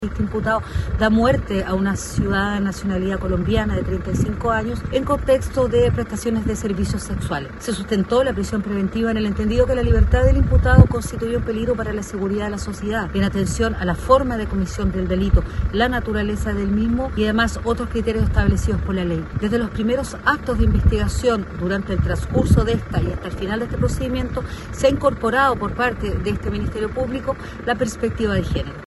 Así lo explicó la fiscal Karyn Alegría, quien detalló que la víctima se trata de una mujer de nacionalidad colombiana, de 35 años.